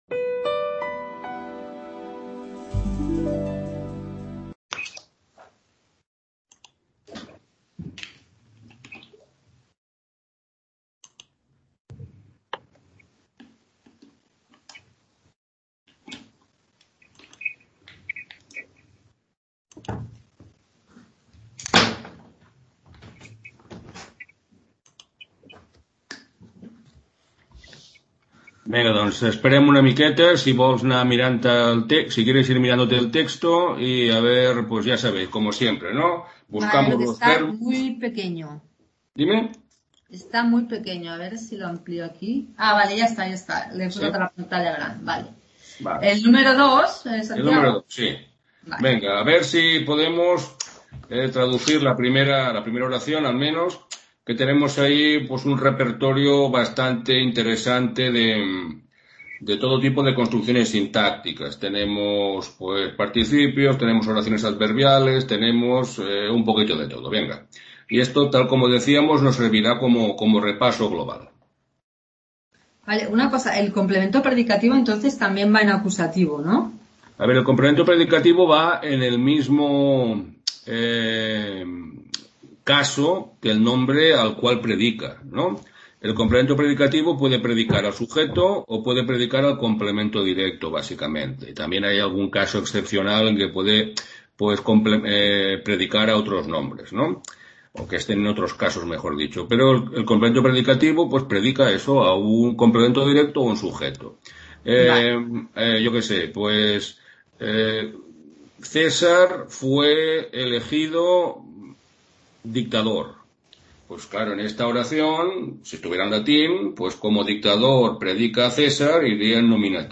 Tutoría latín para hispanistas 30/04/2021 | Repositorio Digital